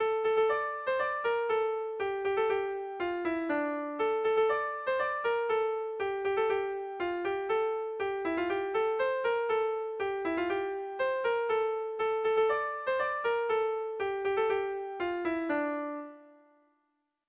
Gabonetakoa
Zortziko ertaina (hg) / Lau puntuko ertaina (ip)
A1A2BA1